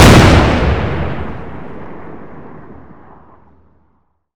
Index of /server/sound/weapons/explosive_m67
m67_explode_4.wav